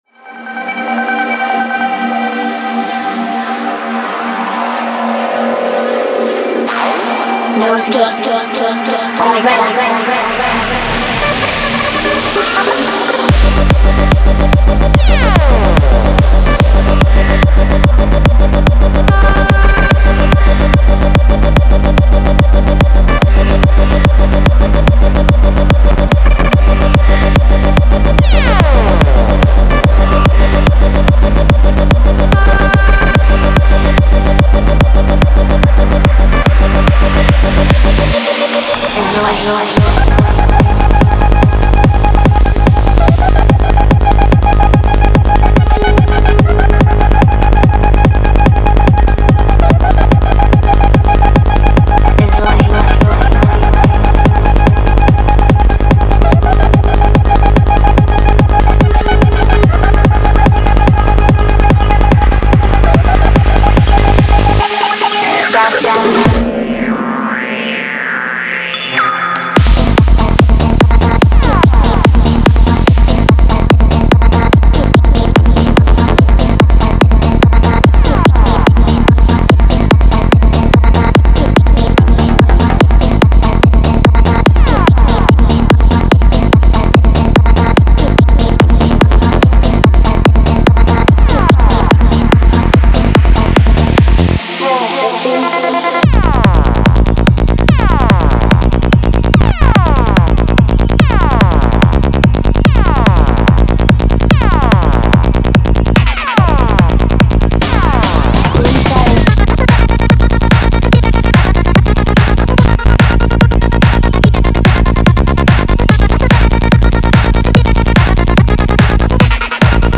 Genre:Psy Trance
デモサウンドはコチラ↓
30 Full Drum Loops 145 Bpm
20 Vocals 145 Bpm